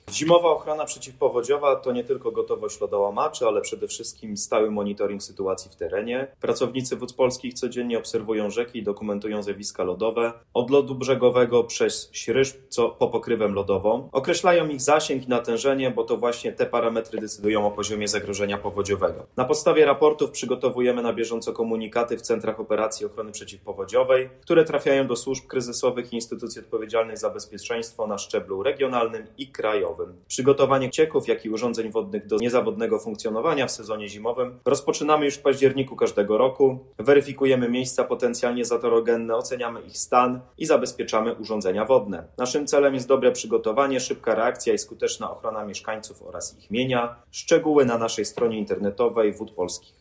Prezes Wód Polskich Mateusz Balcerowicz o przygotowaniach do zimy